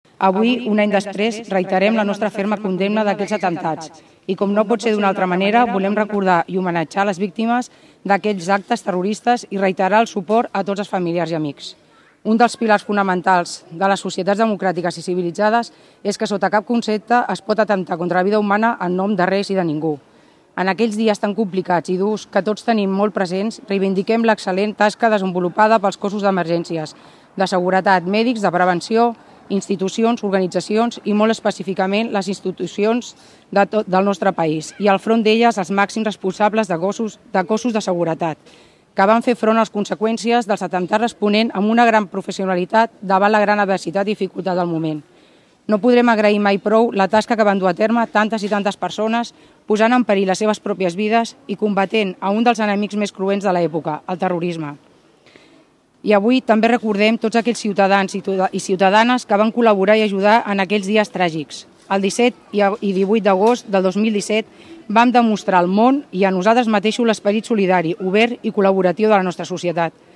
Aquest divendres al migdia, un any després d’aquells atemptats, s’ha fet una convocatòria per recordar i homenatjar les víctimes. A la plaça Major de Palafolls, davant l’Ajuntament, s’ha guardat cinc minuts de silenci.
Durant la lectura del manifest també s’ha emplaçat la ciutadania a seguir endavant sense oblidar els fets ocorreguts el 17 d’agost de 2017.